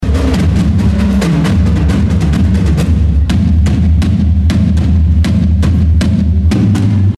wowed the audience.
Drumline-111.mp3